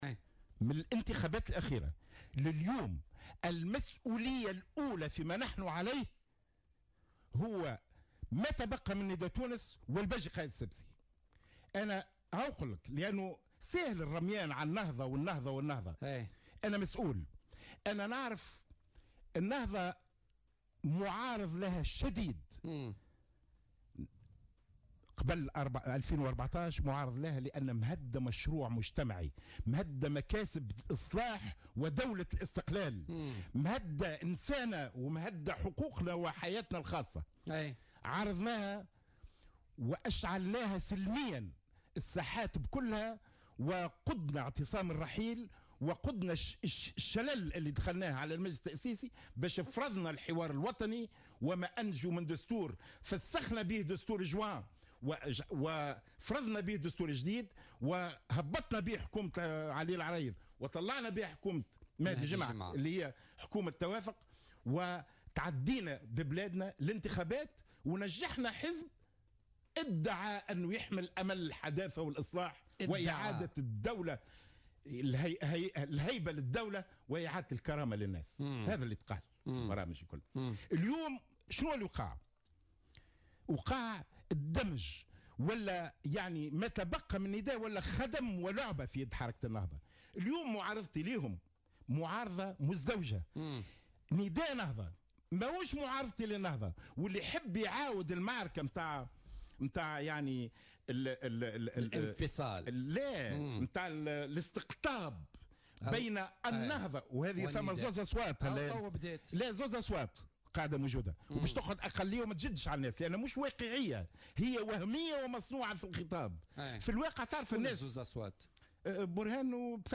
قال النائب خميس قسيلة و المكلف بالشؤون السياسية لحركة تونس أولا ضيف بوليتيكا اليوم الإثنين 5 مارس 2018 أن المسؤول الأول على ما وصلت إليه تونس منذ انتخابات 2014 وإلى حد اليوم هو ماتبقى من نداء تونس و الباجي قايد السبسي.